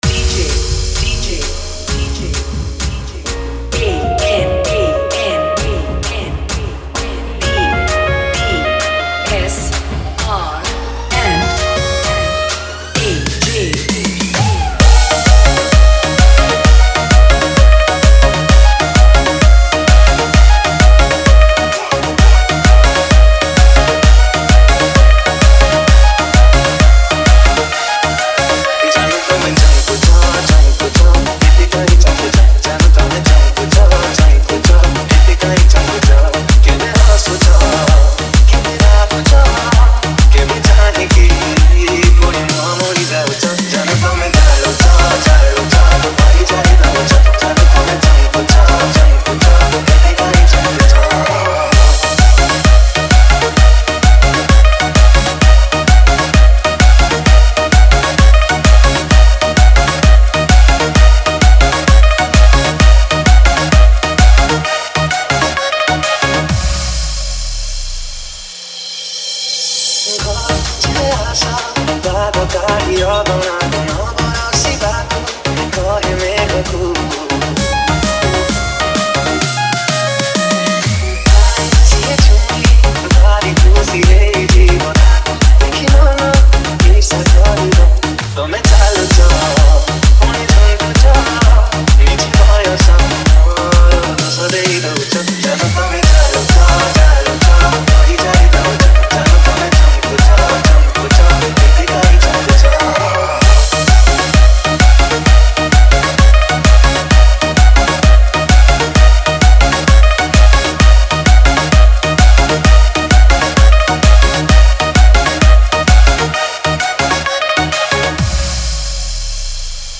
Romantic Love Dj Remix